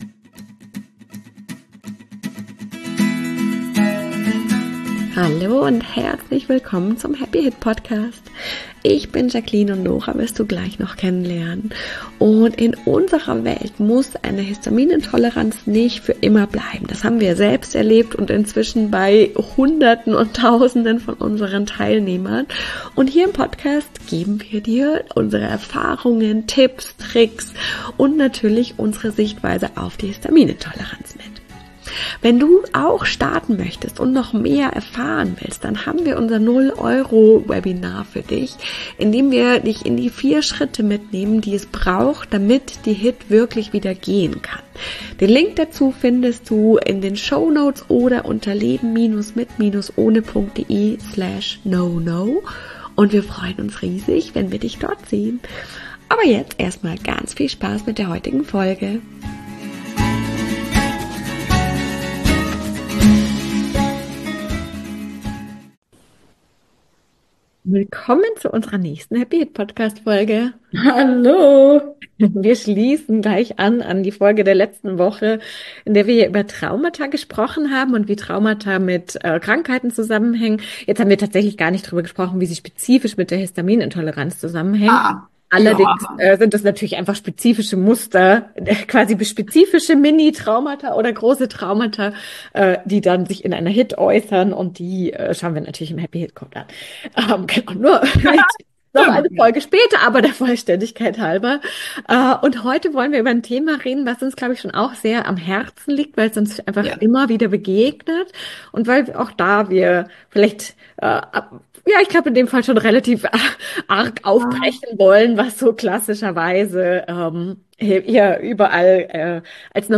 #115 [Talk] Retraumatisierung: Große Gefahr Oder Mit Den Richtigen Methoden Gut Handhabbar? - Leben Mit Ohne